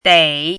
chinese-voice - 汉字语音库
dei3.mp3